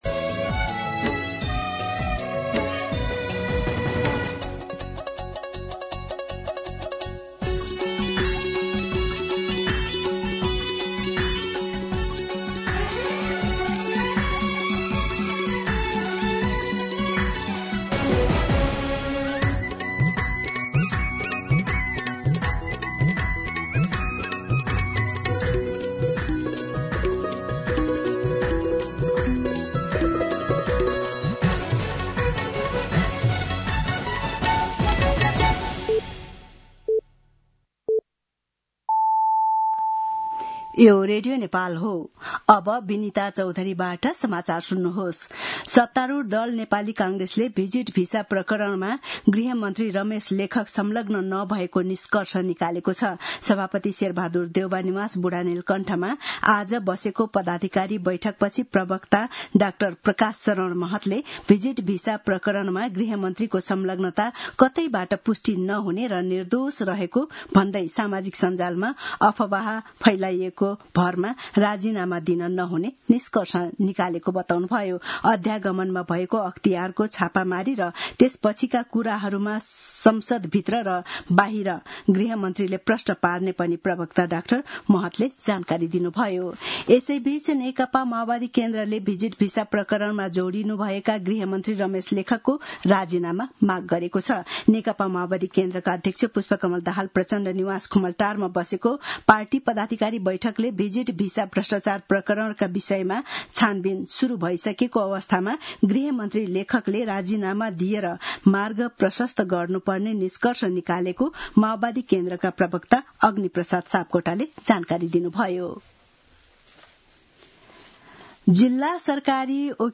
दिउँसो १ बजेको नेपाली समाचार : १२ जेठ , २०८२